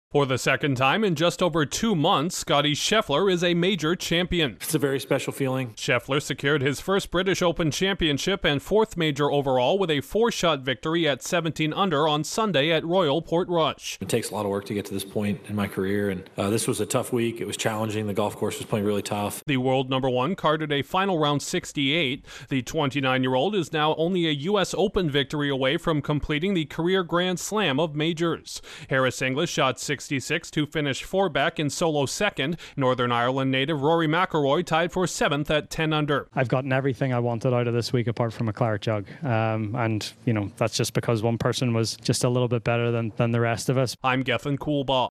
First the PGA Championship, and now a Claret Jug for the world’s top-ranked golfer Scottie Scheffler. Correspondent